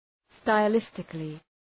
Προφορά
{staı’lıstıklı}